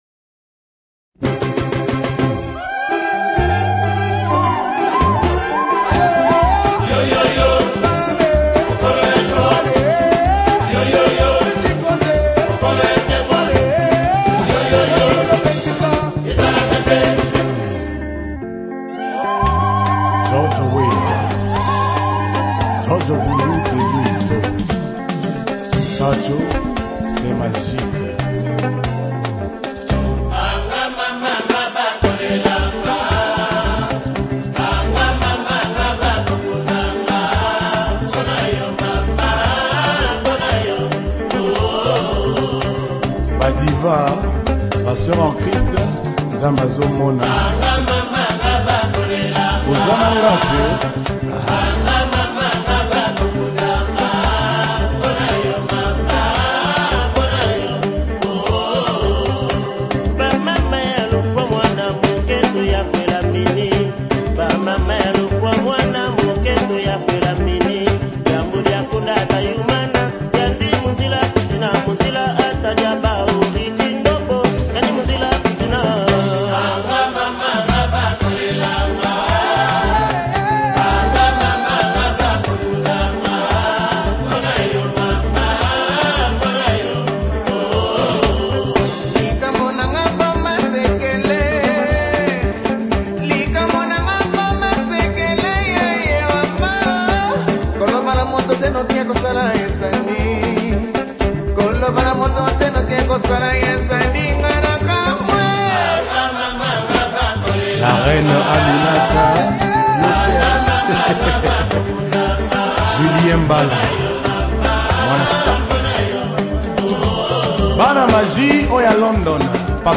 high-energy beats